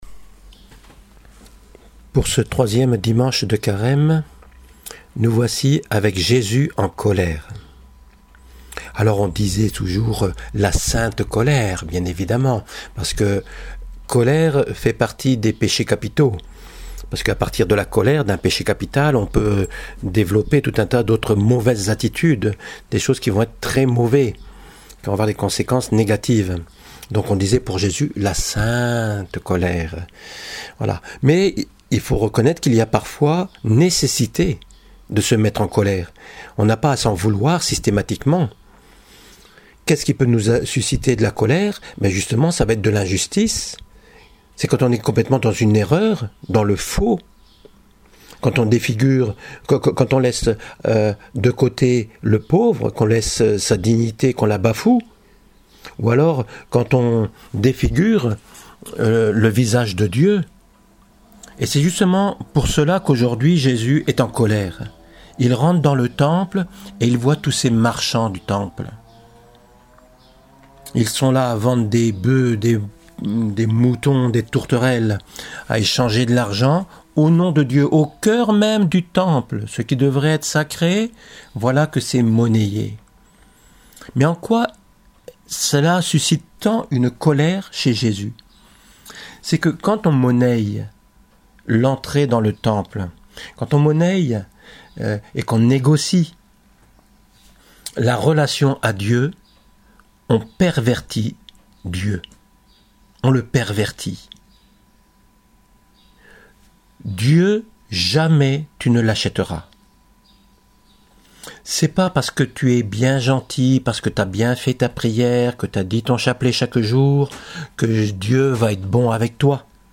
homélie du dimanche